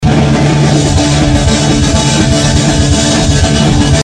men var du tvungen att ta en fil med så dåligt ljud som möjligt för att maskera skillnaden?
Det är ett klipp från en liveupptagning med en handhållen videokamera, eller nåt, från en punkkonsert.